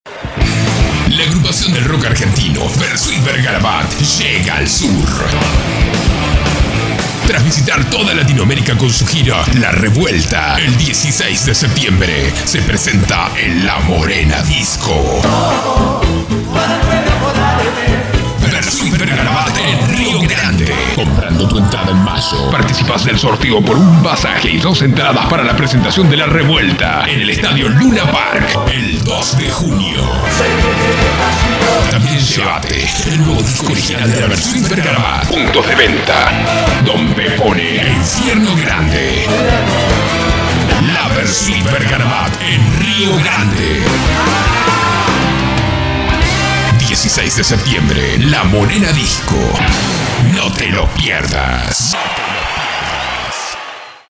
spanisch SĂŒdamerika
chilenisch
Sprechprobe: Industrie (Muttersprache):
I have 4 different styles of voice and I can come in neutral tones and as agile and high tones.